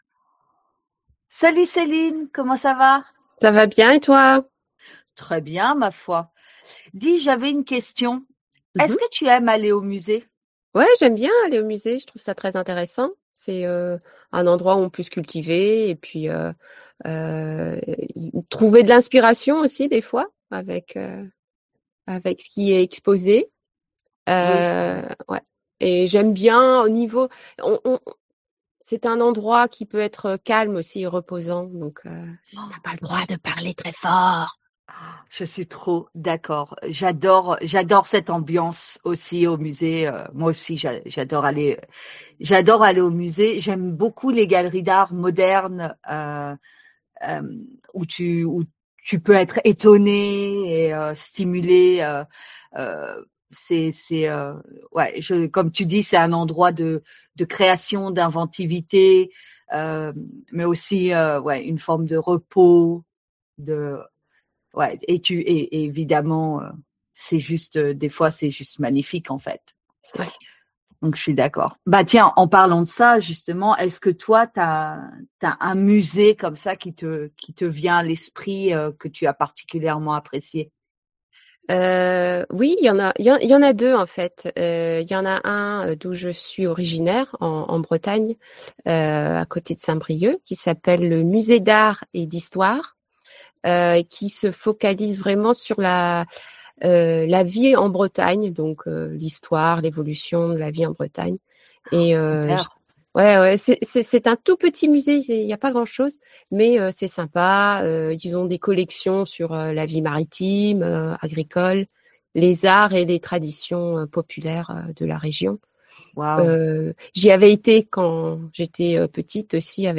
French Conversation Podcast